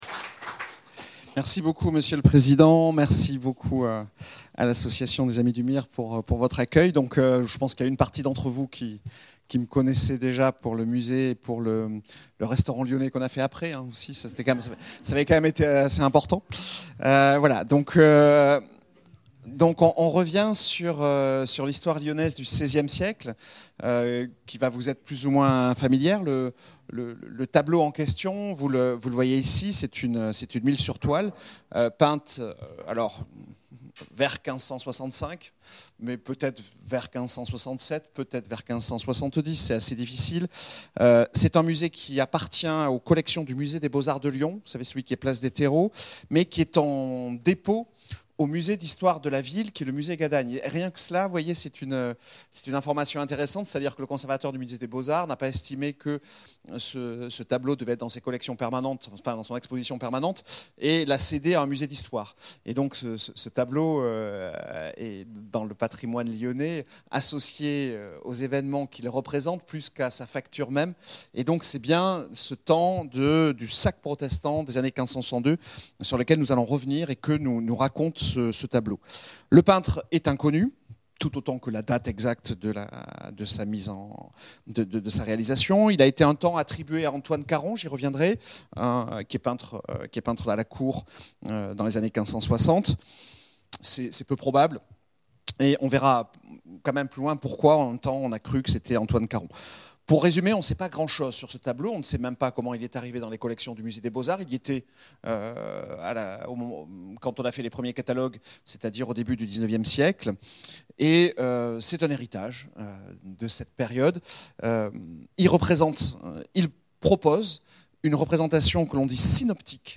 Conférences